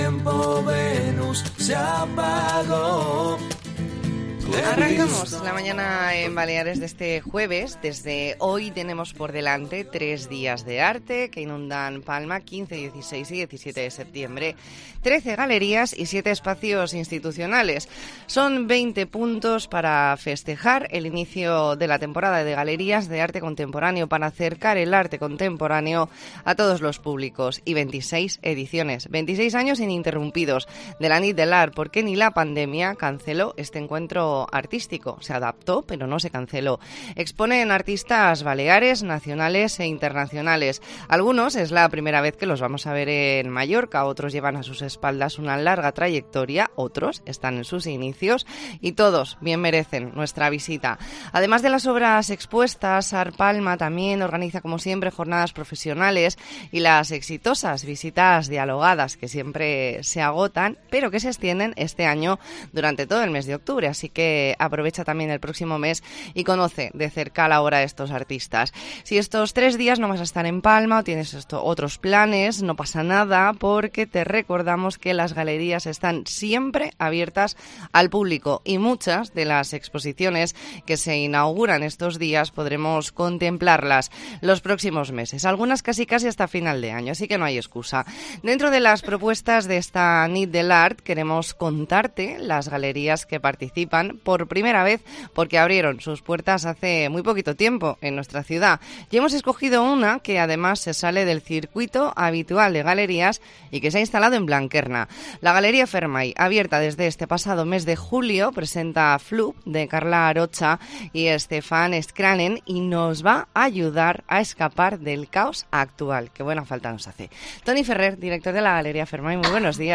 E ntrevista en La Mañana en COPE Más Mallorca, jueves 15 de septiembre de 2022.